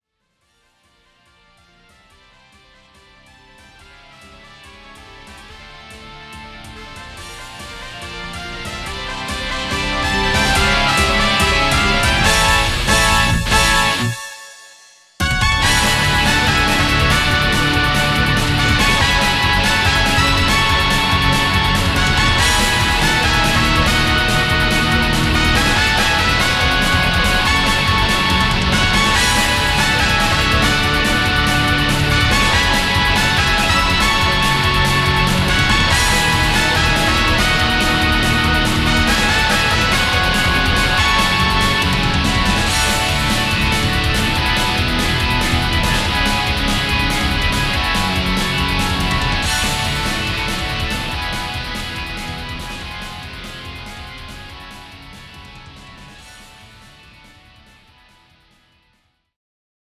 「和」「雅」といった叙情的なものとElectricGuitarサウンドの調和・融合を目指した
Play All Instruments